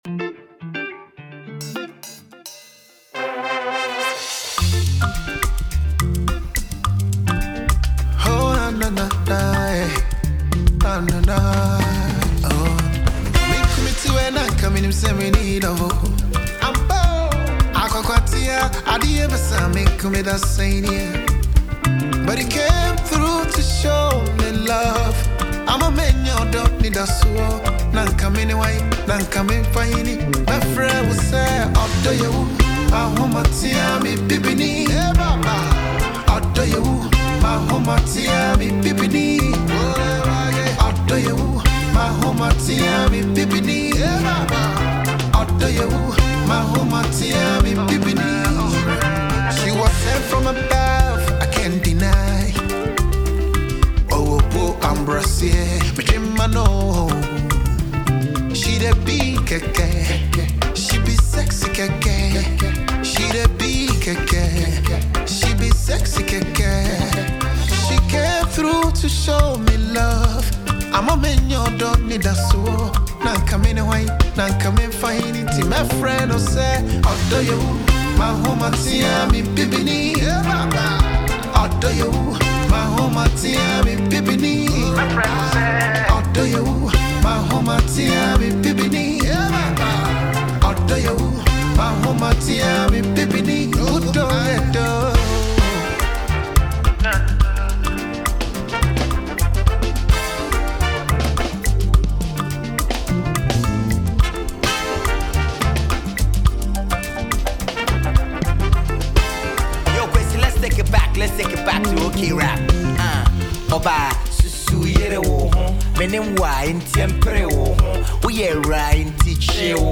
Check out the new tune from Ghanaian highlife musician
rap